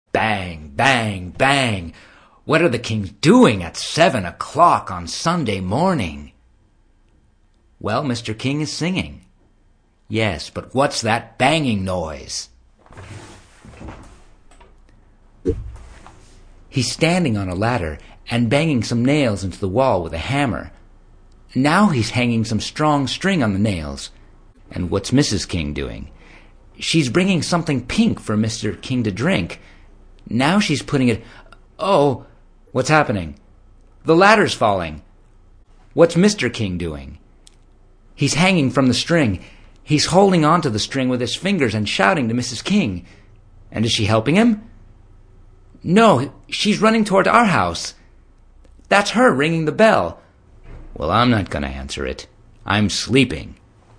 Hi Classes – below are recordings of myself reading the dialogs you have chosen. Listen to the examples and practice with the stress I use.